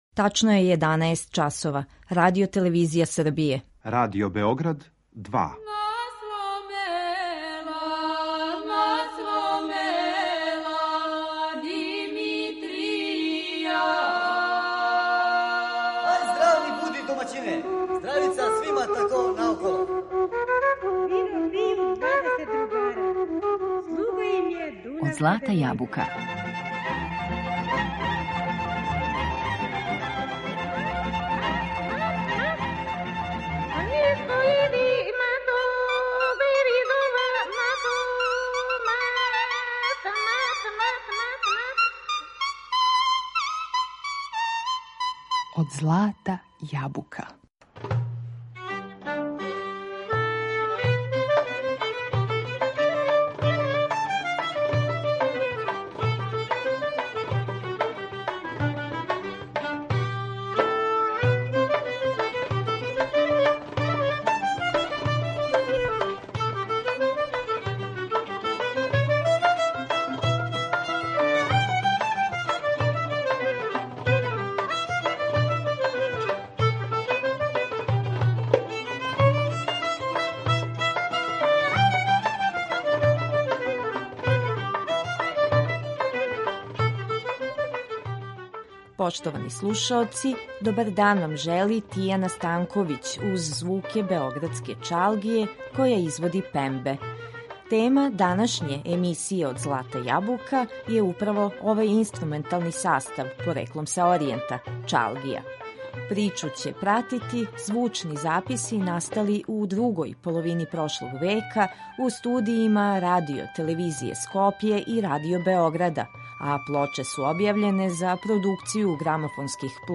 Кроз причу ће вас водити звучни записи настали у другој половини прошлог века у студијима Радио-телевизије Скопље и Радио Београда, а плоче су објављене за Продукцију грамофонских плоча Радио-телевизије Београд и Југотон.